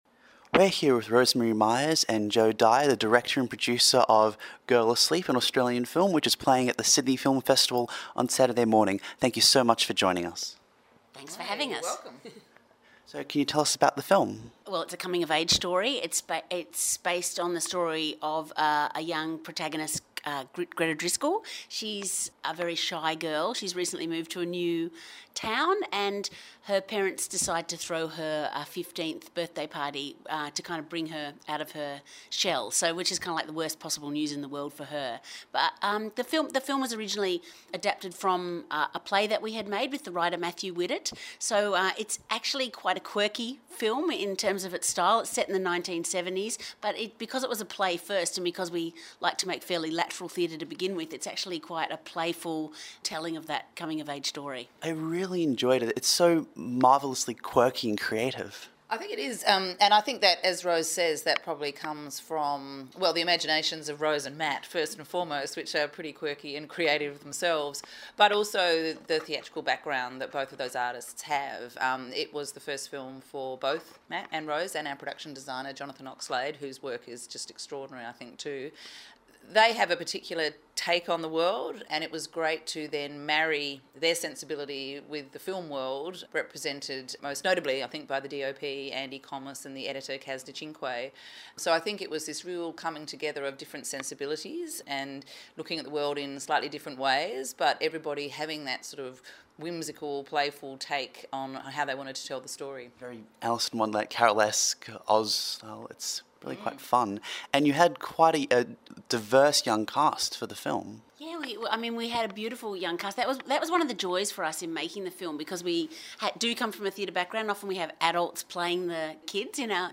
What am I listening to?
Festivals, Interviews